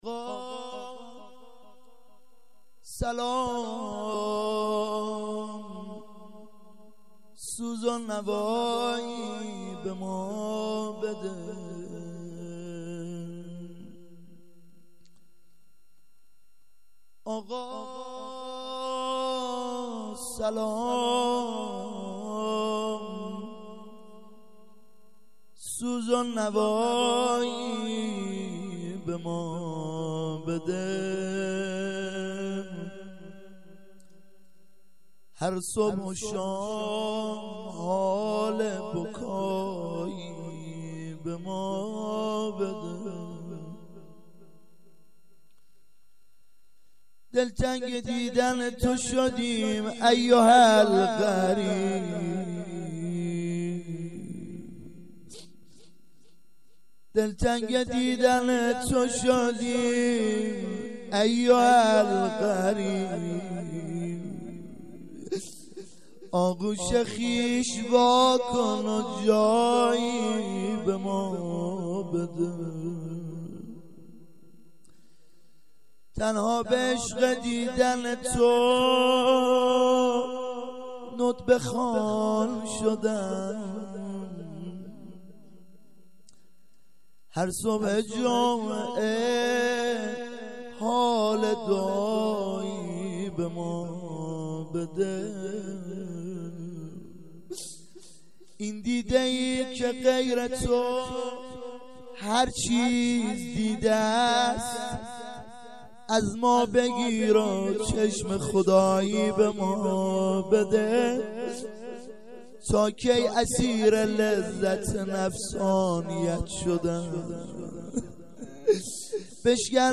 • هفتگی 92/07/16 هیات العباس